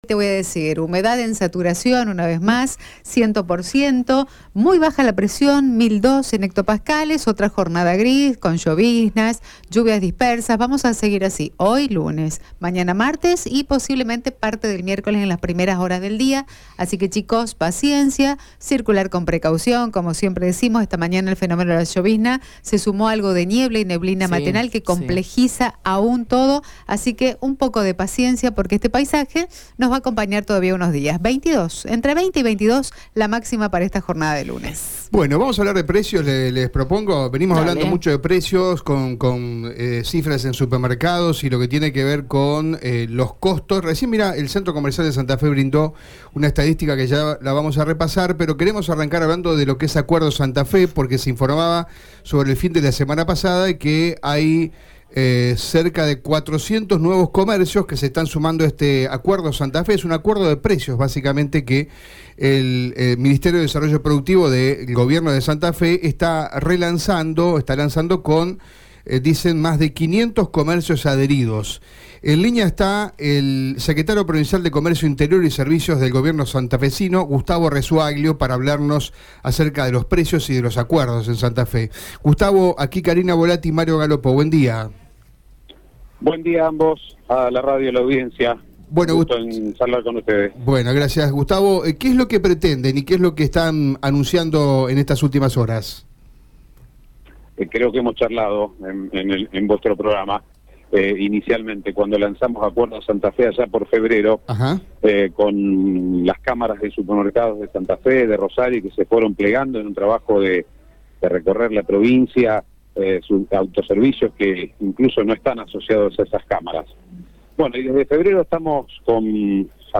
Al respecto, el secretario de Comercio Interior, Gustavo Rezzoaglio, confirmó en Radio EME que Billetera Santa Fe volverá a ofrecer reintegros en todas las compras que se realicen en supermercados y autoservicios adheridos, con un tope mensual de 4.000 pesos.